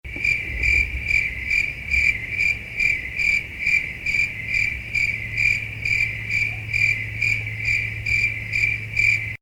Crickets - Reality Bytes